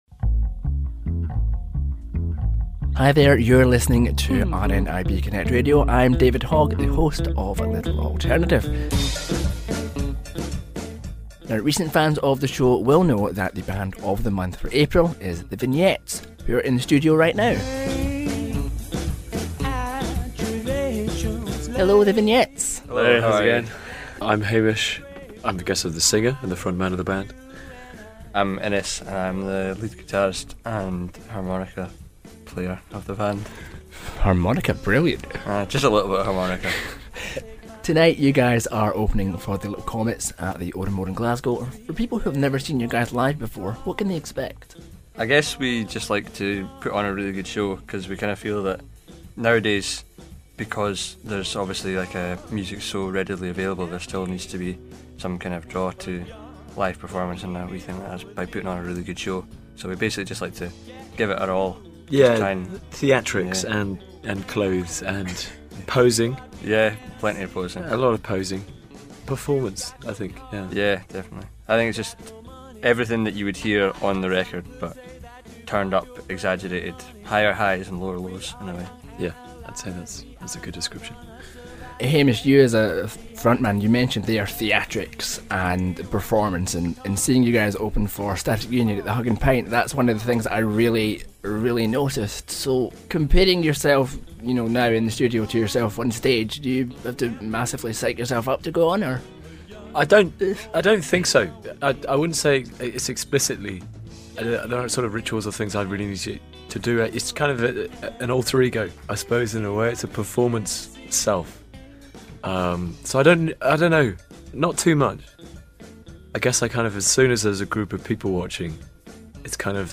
Band Of The Month for April The Vignettes popped by the A Little Alternative studio this week